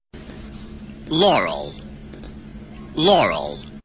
Новый хит — звуковая иллюзия, которую часть пользователей распознает как «лорл», а другие не менее четко слышат «янни».
Иллюзия являет собой произнесенное «машинным» голосом слово «Laurel» (или «Yanny») и записанное в аудиофайл, который вызывает ожесточенные споры в социальных сетях.
Благодаря снижению и повышению тона на 20% можно услышать обе фразы.годаря снижению и повышению тона на 20% можно услышать обе фразы.